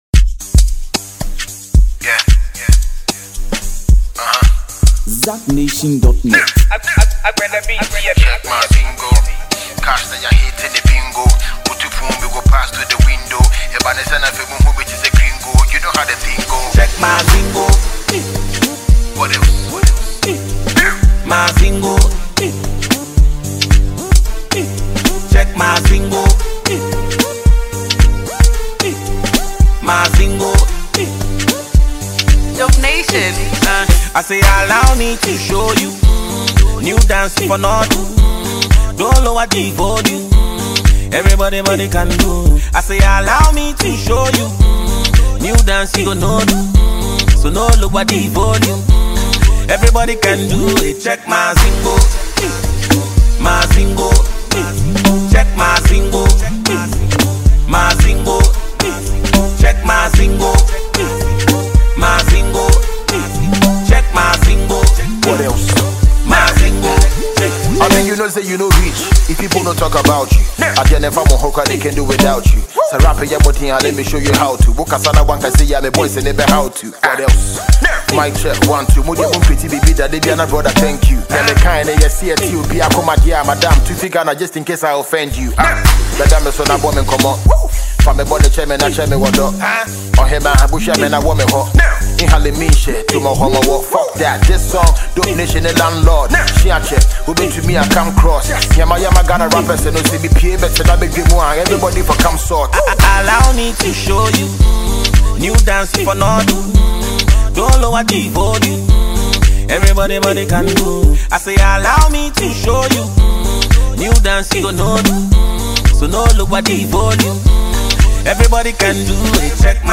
Ghanaian twin musicians